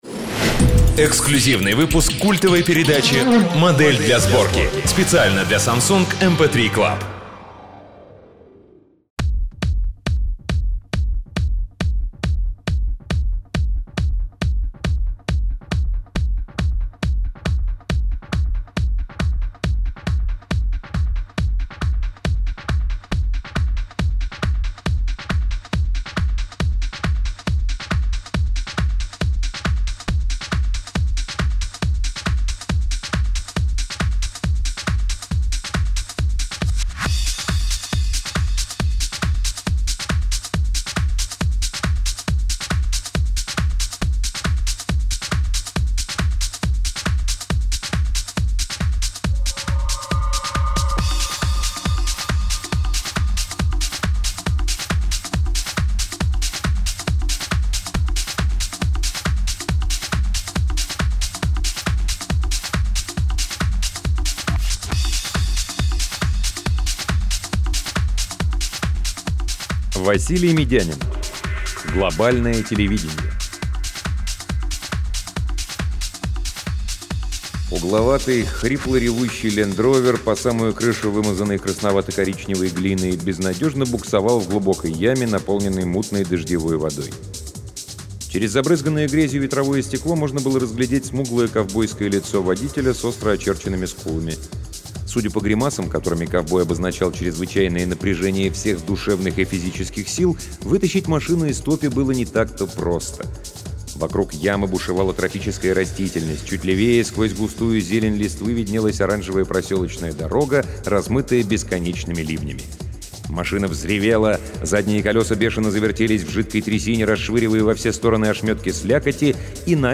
Аудиокнига Василий Мидянин — Глобальное телевидение